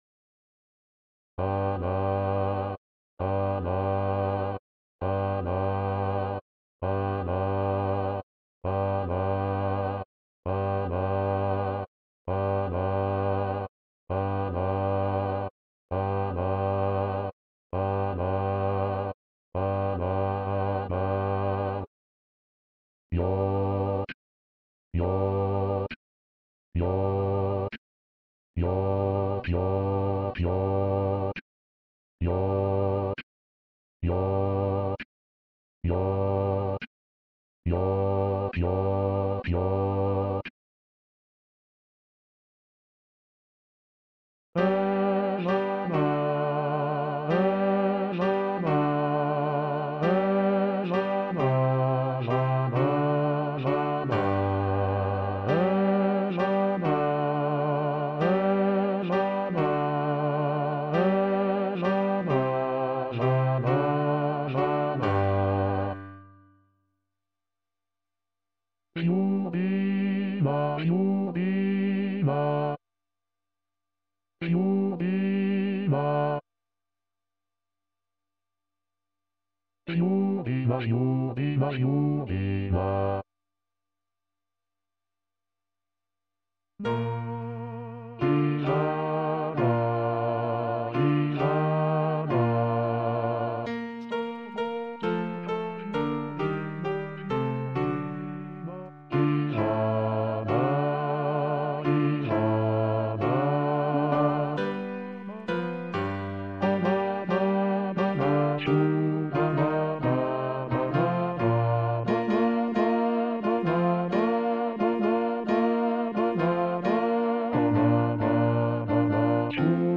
arrangement pour choeur
Basse
bourgeois_Basse.mp3